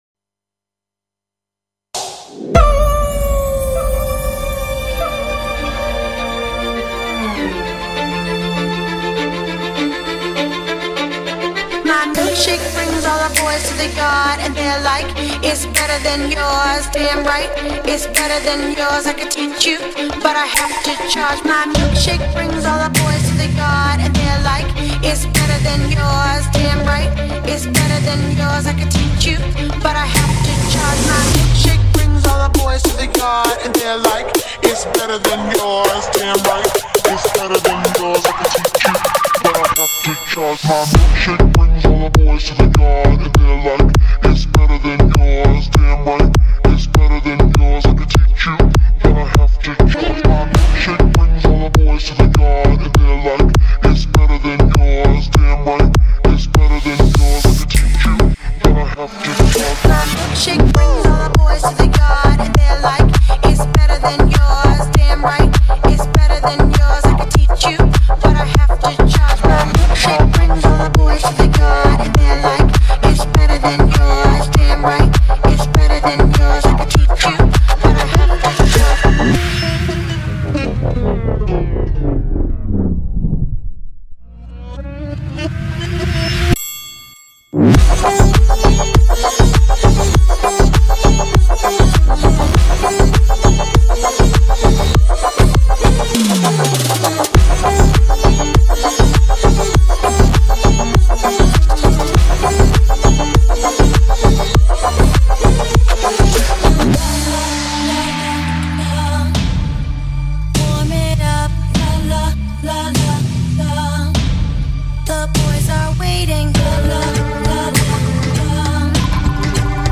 энергичный ремикс классической хип-хоп и R&B композиции